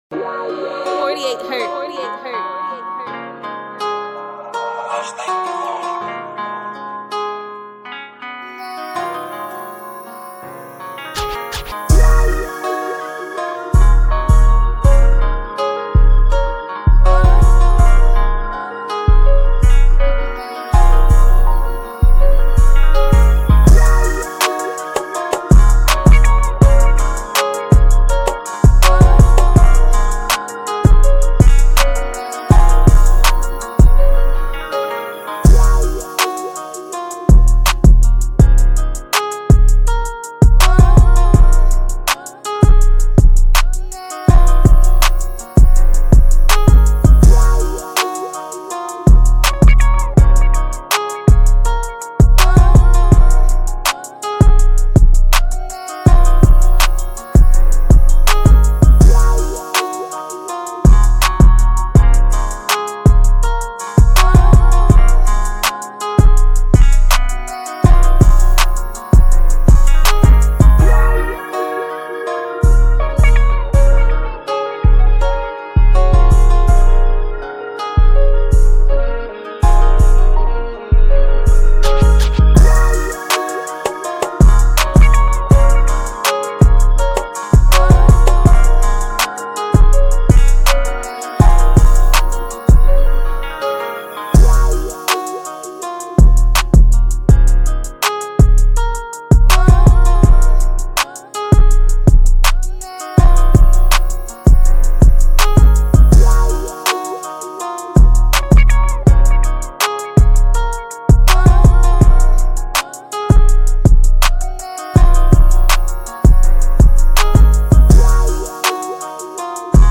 2020 in Hip-Hop Instrumentals